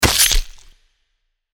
دانلود آهنگ دعوا 4 از افکت صوتی انسان و موجودات زنده
جلوه های صوتی
دانلود صدای دعوای 4 از ساعد نیوز با لینک مستقیم و کیفیت بالا